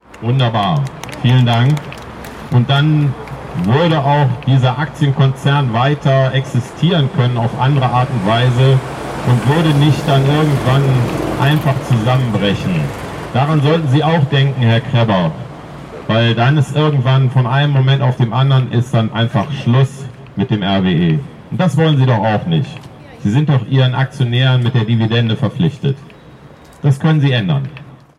Mahnwache Essen: „RWE einwallen“ (Audio 10/18)